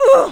hurt1.wav